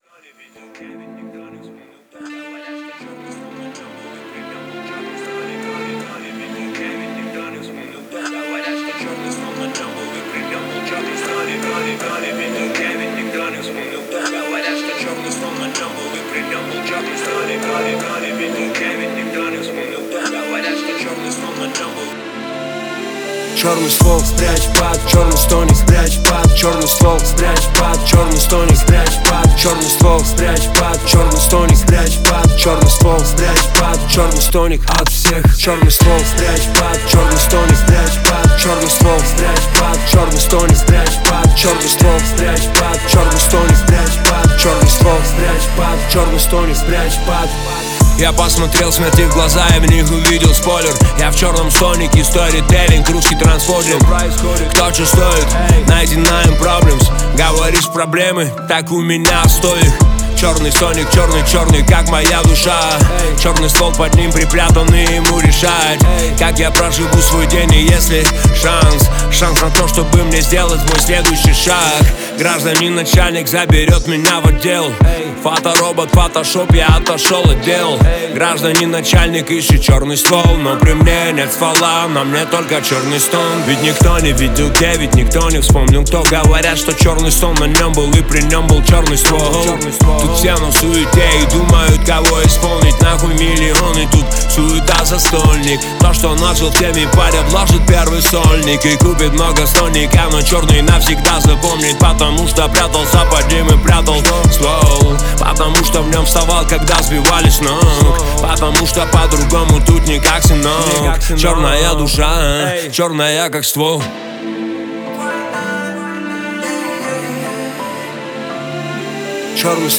Рэп, Русский рэп 2025